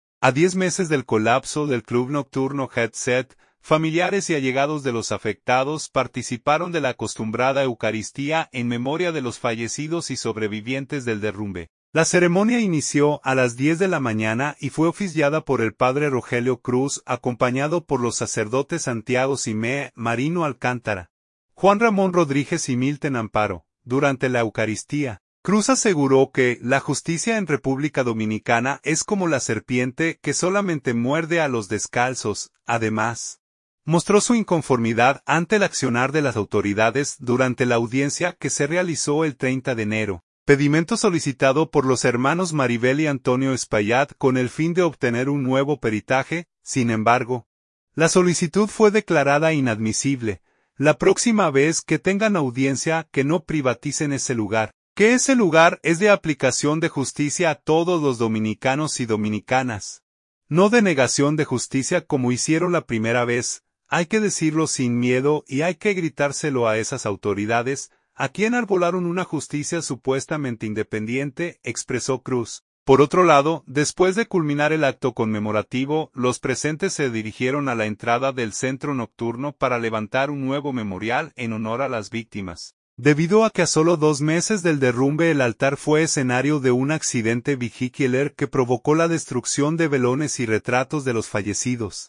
A diez meses del colapso del club nocturno Jet Set, familiares y allegados de los afectados participaron de la acostumbrada eucaristía en memoria de los fallecidos y sobrevivientes del derrumbe.